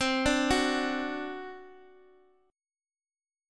Csus2b5 Chord